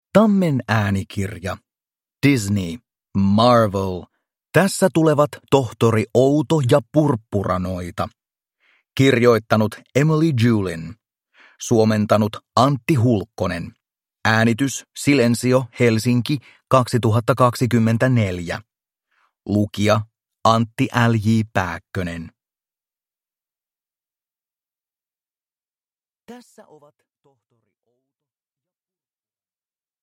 Tässä tulevat Tohtori Outo ja Purppuranoita. Luen itse. – Ljudbok